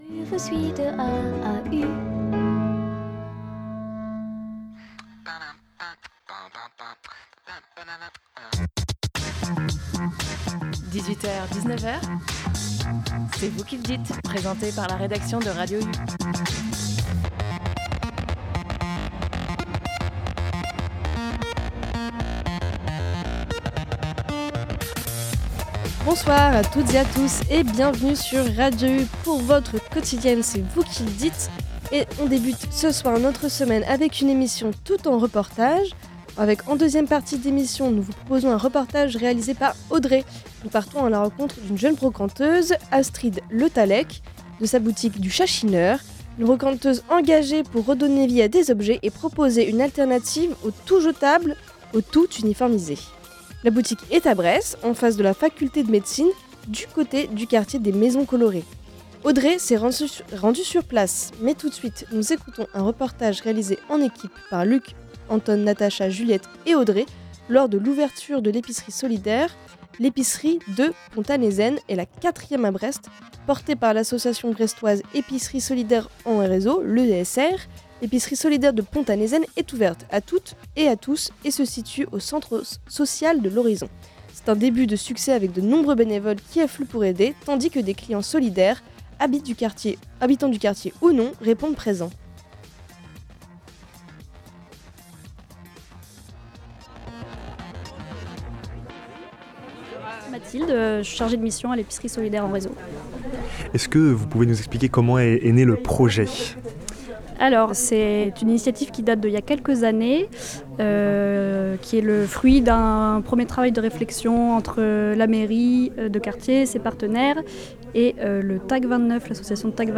Dans cette émission entièrement consacrée aux reportages